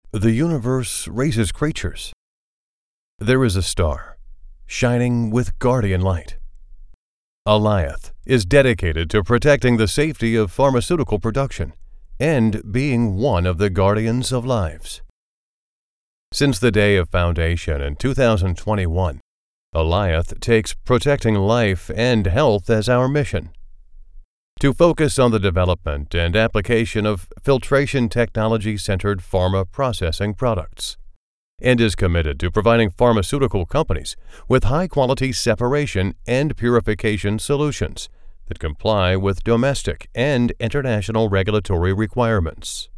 w186-自然叙述-干音
外籍英男186 w186
w186-自然叙述-干音.mp3